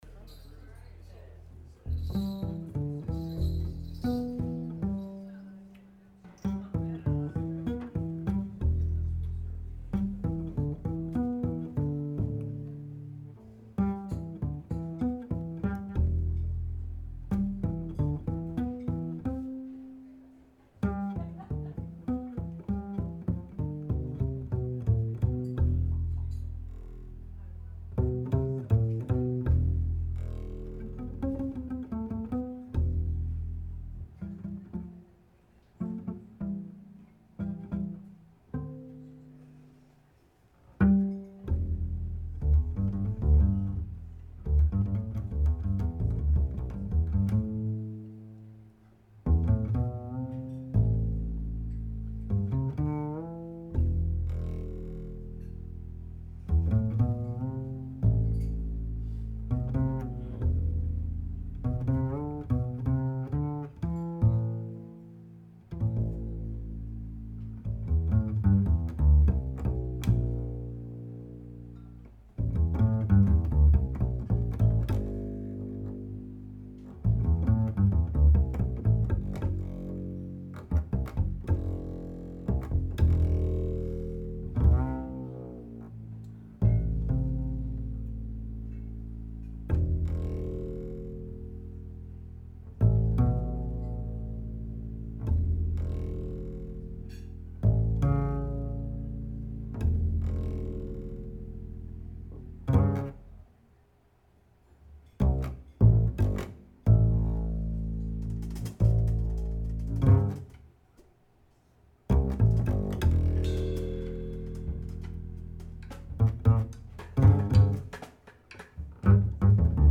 Bass
Flugelhorn, Accordion
Drums
Guitar